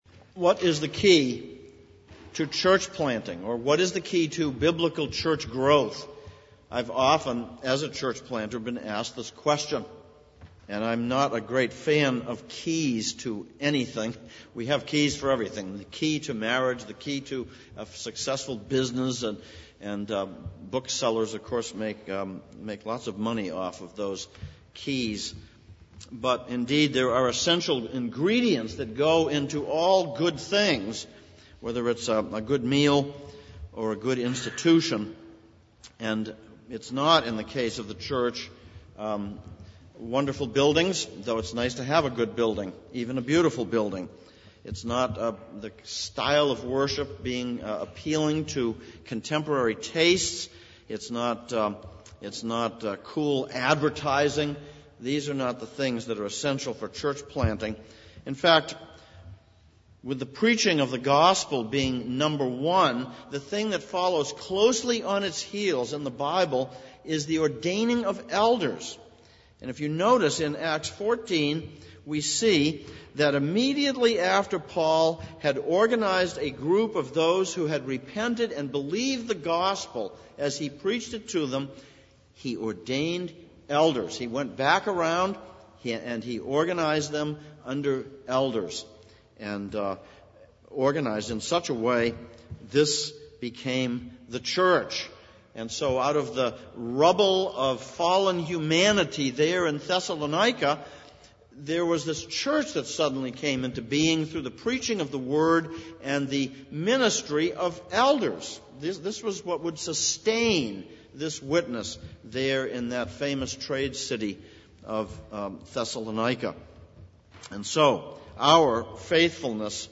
Ordination Sermon: 1 Thessalonians 5:12-28; Exodus 18:1-27